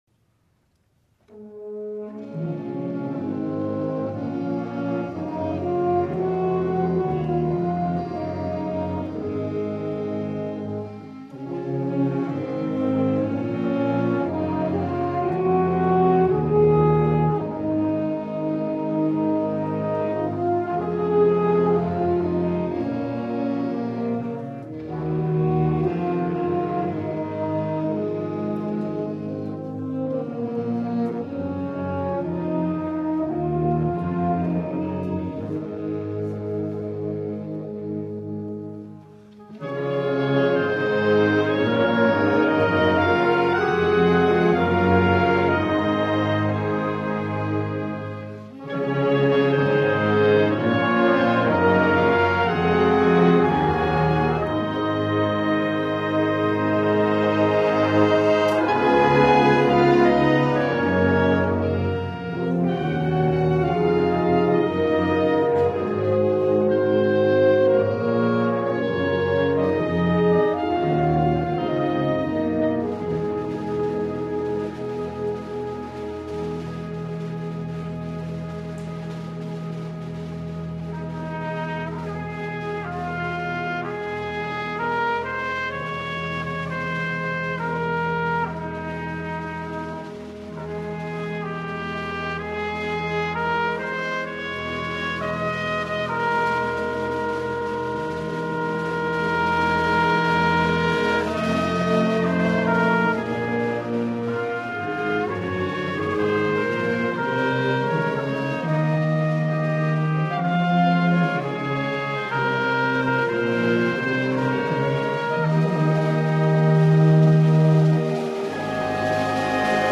From Concert #2, May 5, 2011 MP3 files